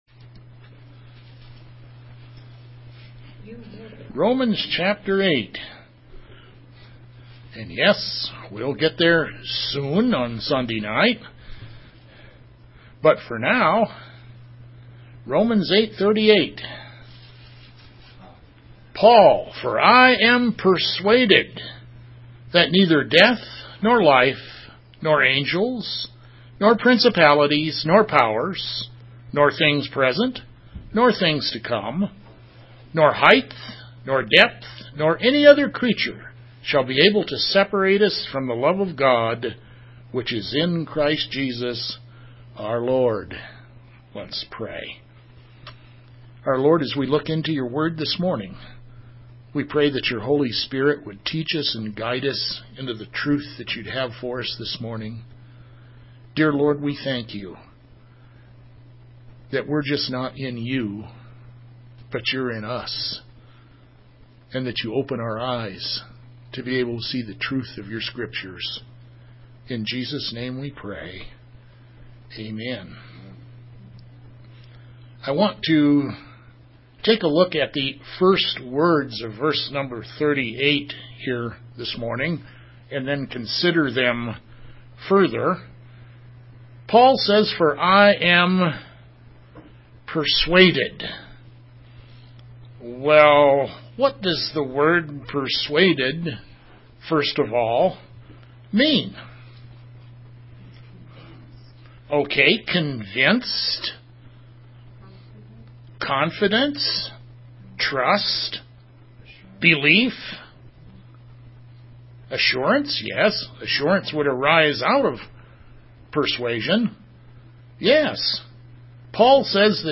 2018-02-18 AM Service